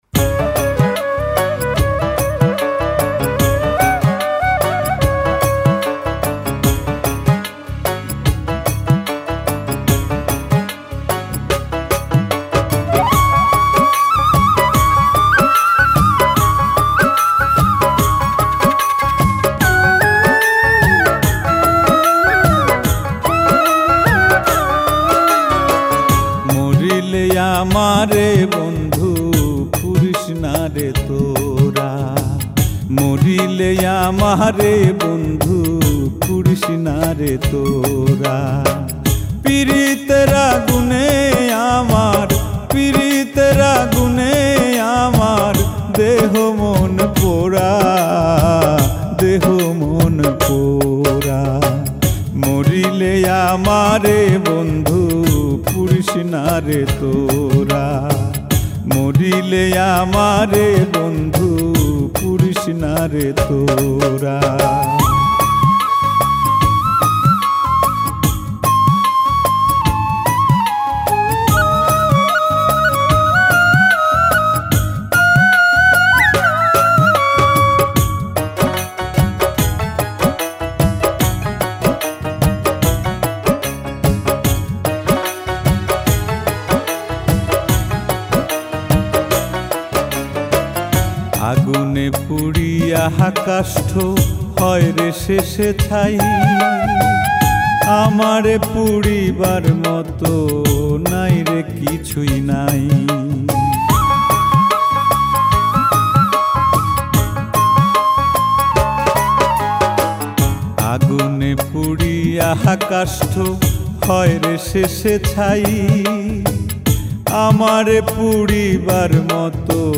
Bengali Folk Songs